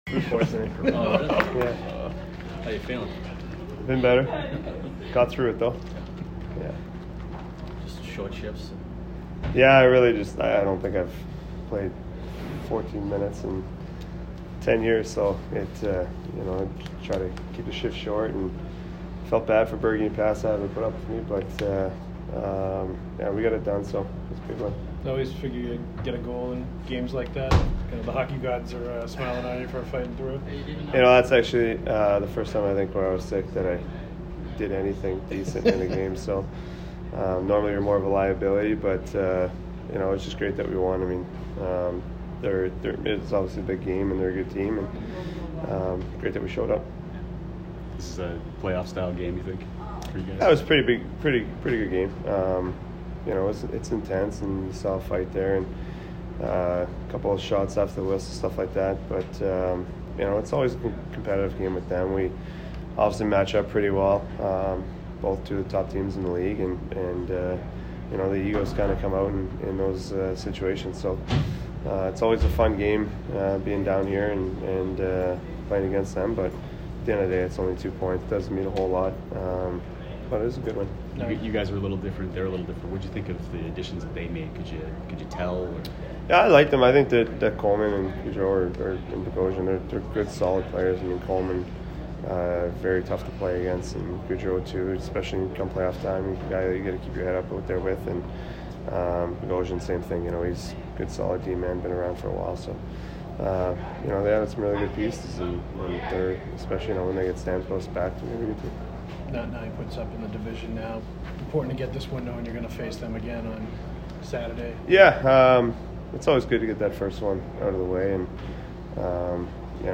Brad Marchand post-game 3/3